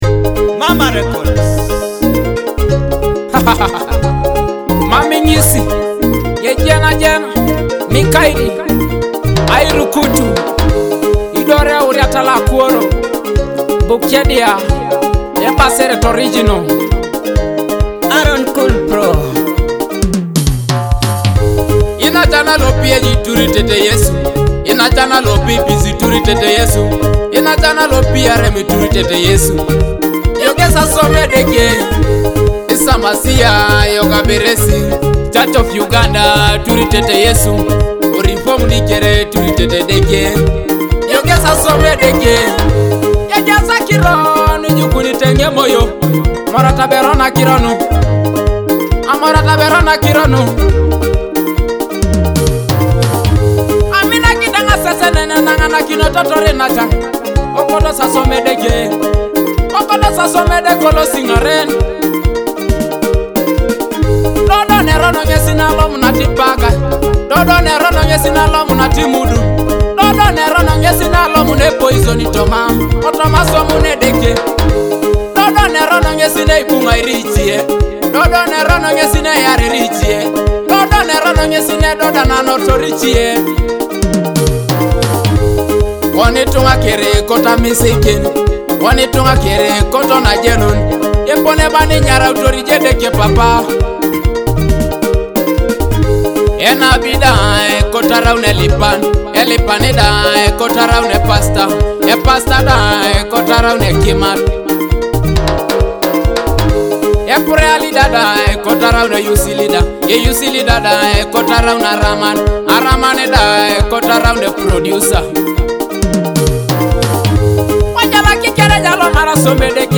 feel the uplifting spirit of this must-have anthem.